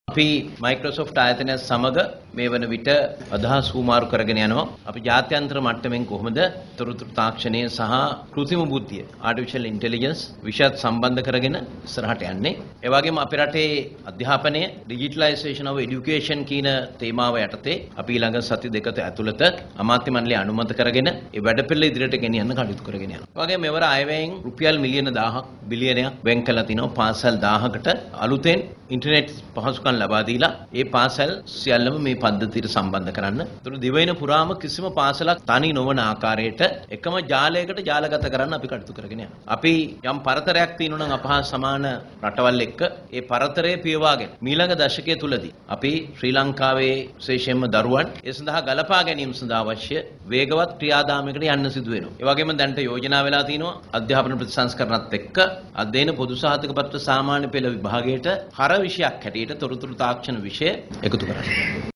අධ්‍යාපන අමාත්‍යාංශයේ පැවති උත්සවයකට එක්වෙමින් අමාත්‍යවරයා මේ බව සදහන් කළා.